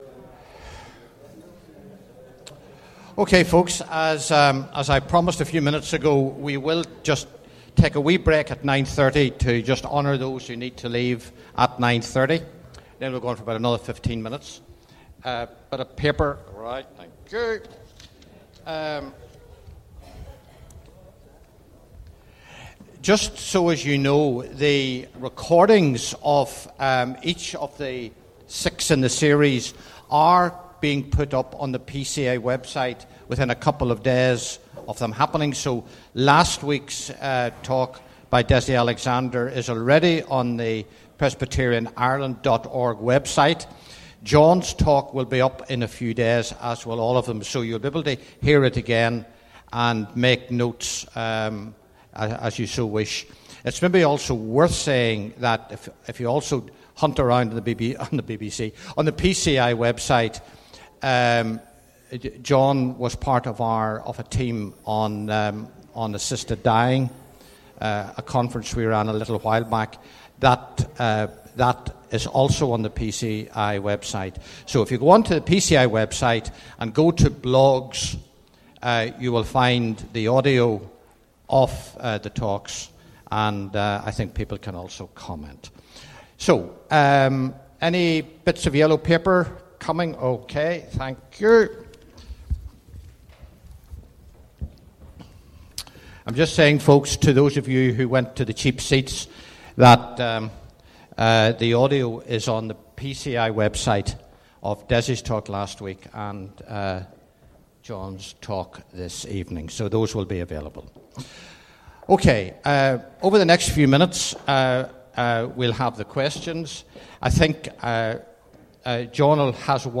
Seminar 2: Q & A Session